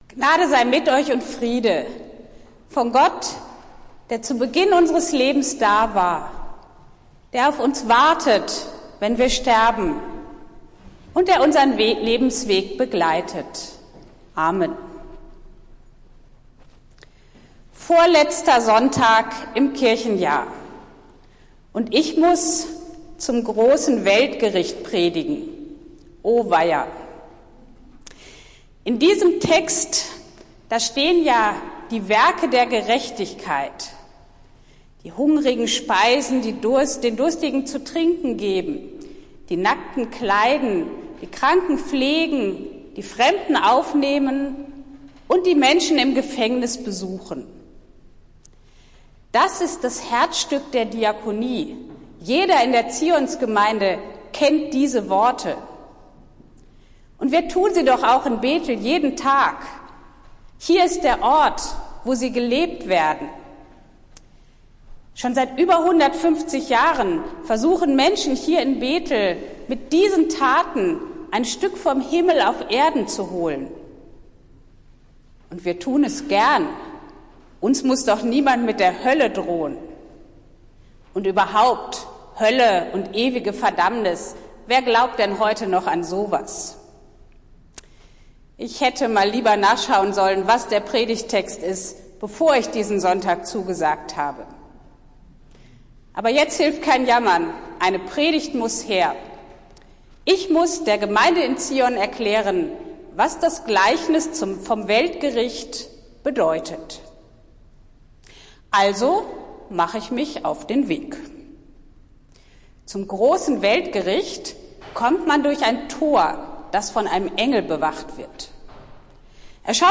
Predigt des Gottesdienstes aus der Zionskirche vom Sonntag, den 19. November 2023